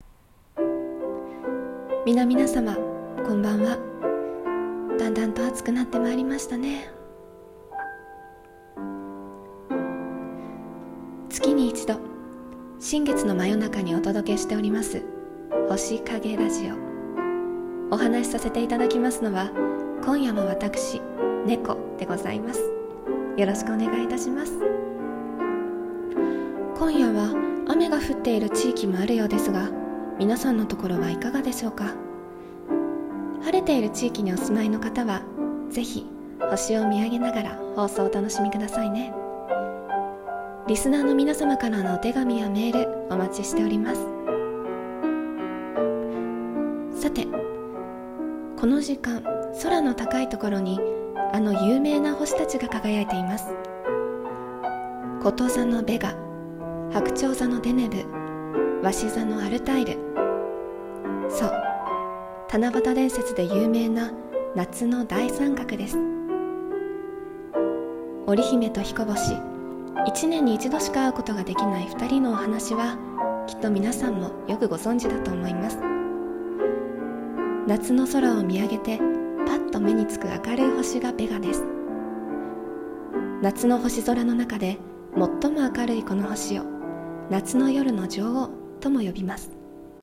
朗読台本「星影ラジオ 夏」